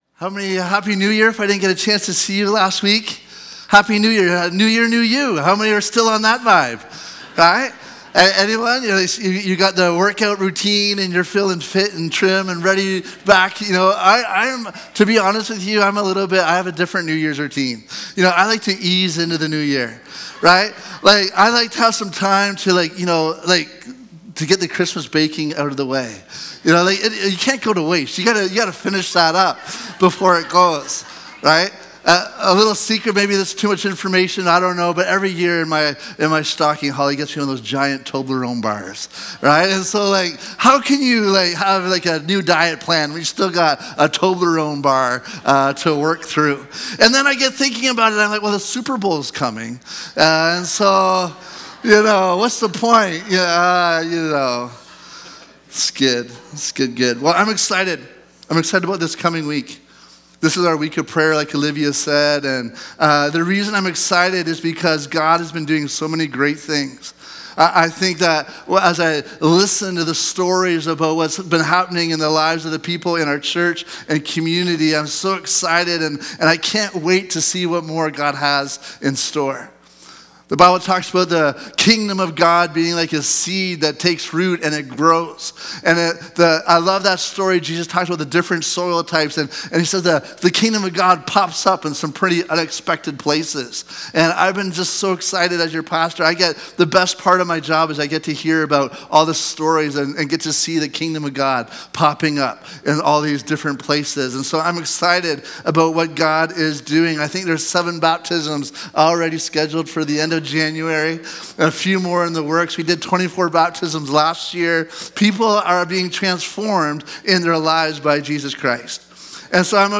Sermons | Bethel Church Penticton